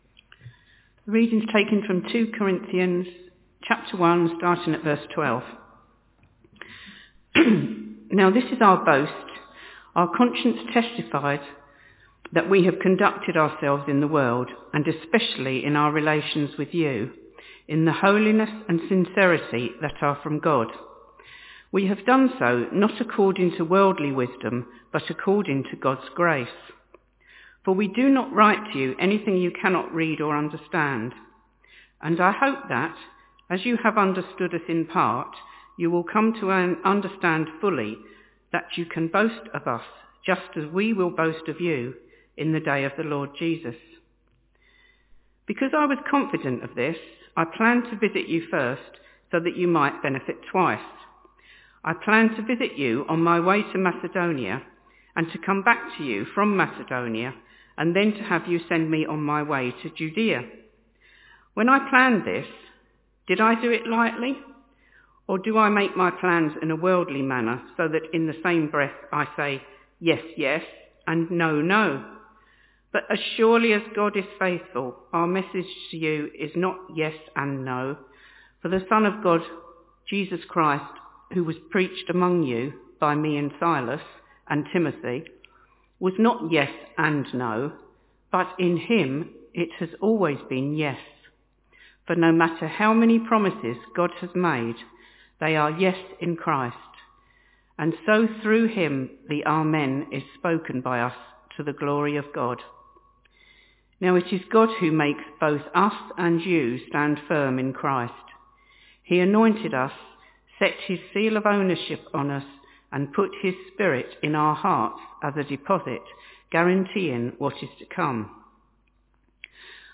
Talk starts with prayer after reading at 3.50